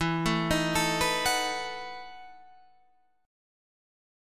EM7sus2sus4 Chord